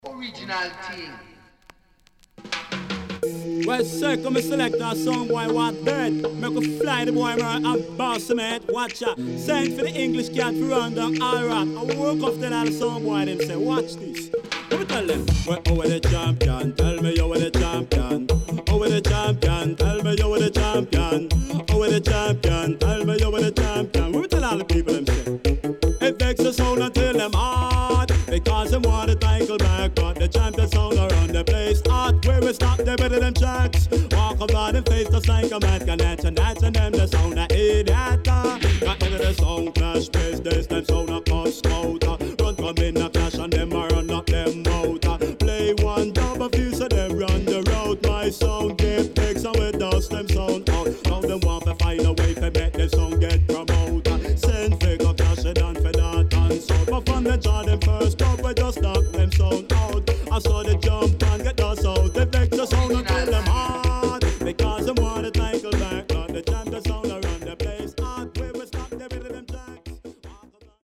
CONDITION SIDE A:VG(OK)〜VG+
【12inch】
SIDE A:所々チリノイズがあり、少しプチノイズ入ります。